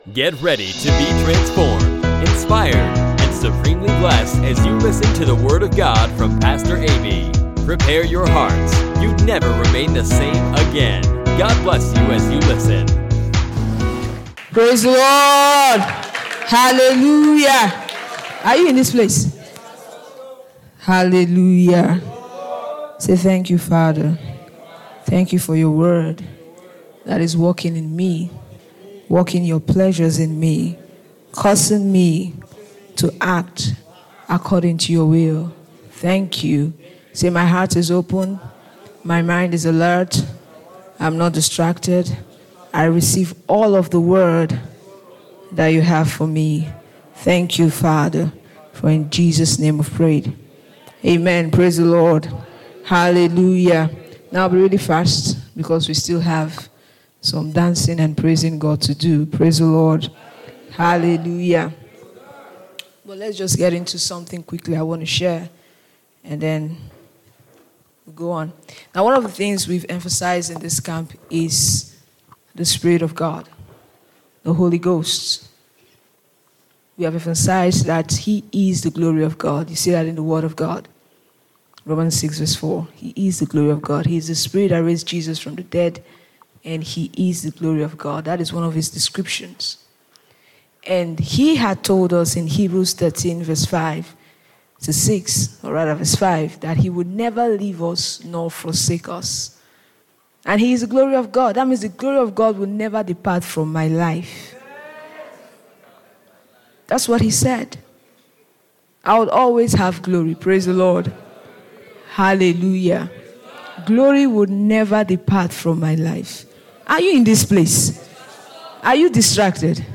MMCM 2022 Day 3 Thanksgiving Service